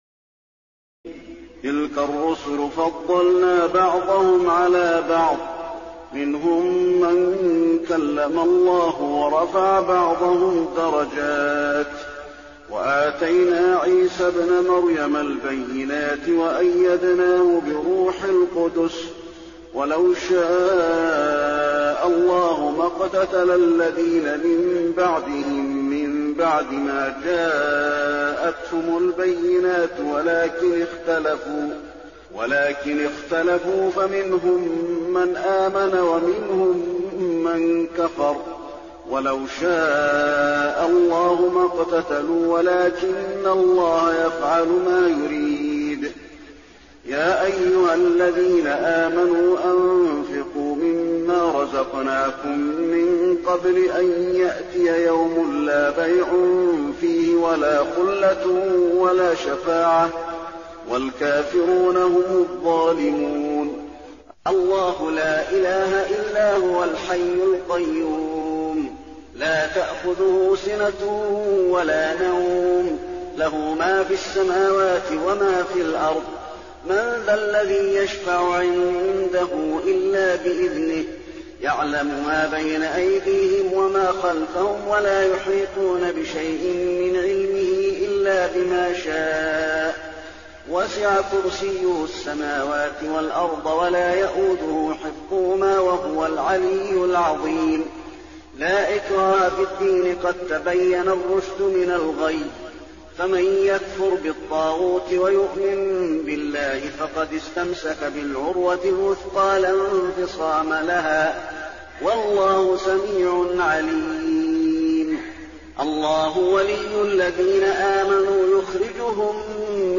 تراويح الليلة الثالثة رمضان 1422هـ من سورتي البقرة (253-286) و آل عمران (1-17) Taraweeh 3st night Ramadan 1422H from Surah Al-Baqara and Surah Aal-i-Imraan > تراويح الحرم النبوي عام 1422 🕌 > التراويح - تلاوات الحرمين